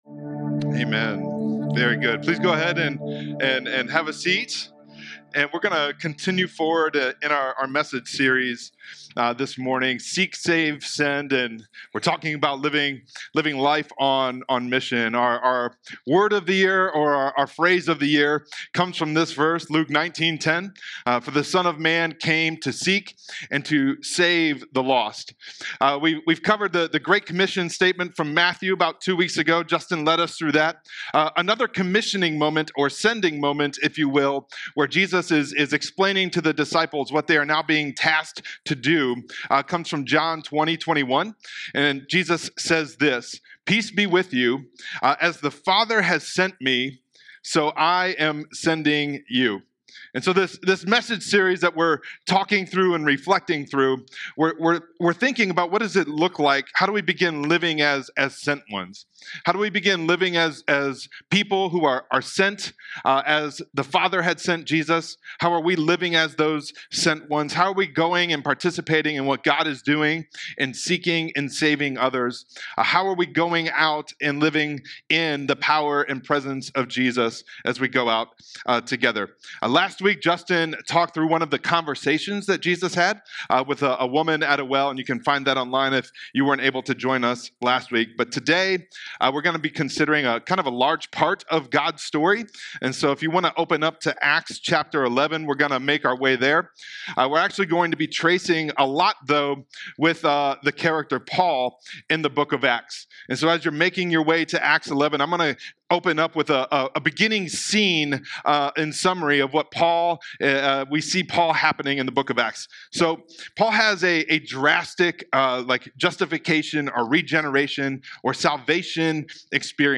Sermons | First Church Bellevue